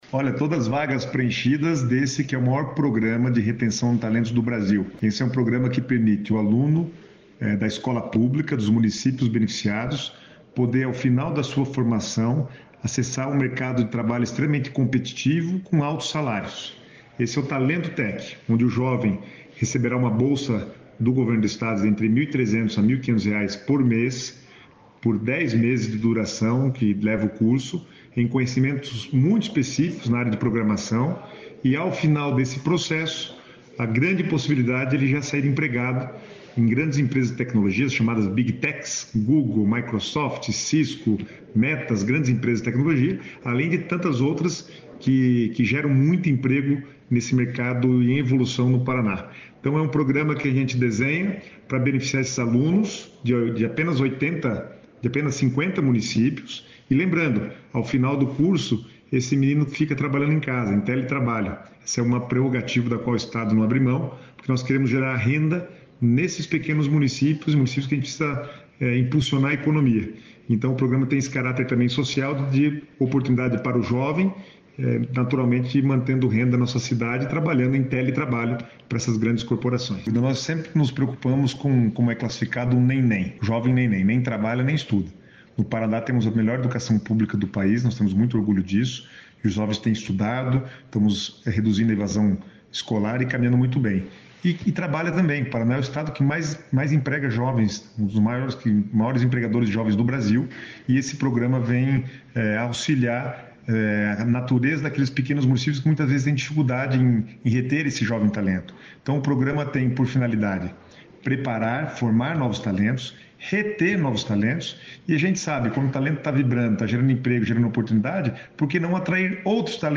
Sonora do secretário de Estado do Planejamento, Guto Silva, sobre o Talento Tech-PR